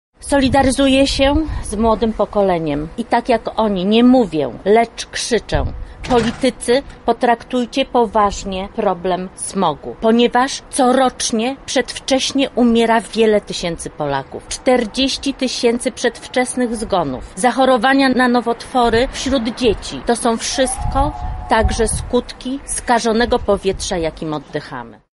O skutkach oddychania zanieczyszczonym powietrzem mówi kandydatka do sejmu z ramienia Koalicji Obywatelskiej Bożena Lisowska